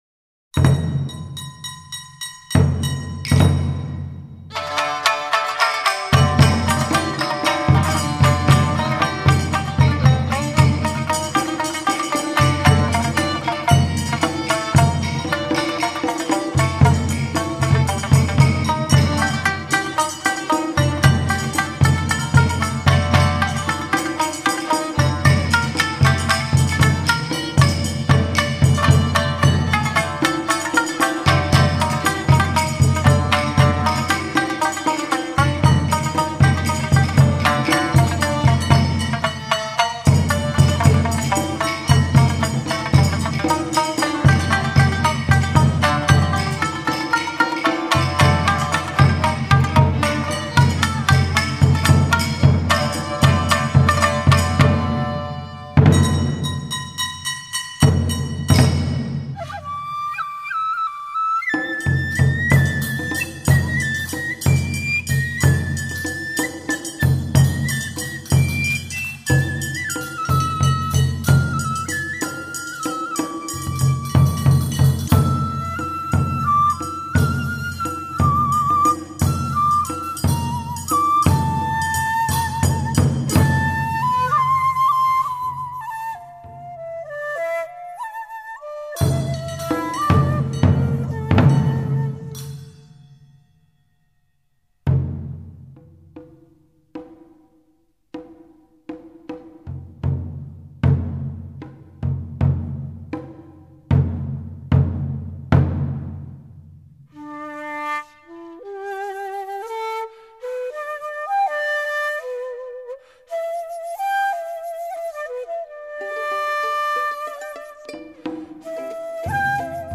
该团由十至十五位左右团员组成，除为数约十位的鼓手外，另有五位「客席」团员，担任尺八、筝、以及其它乐器。